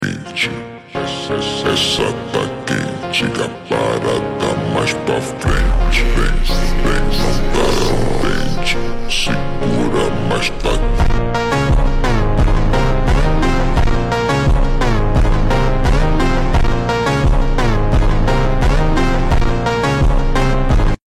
(Super Slowed)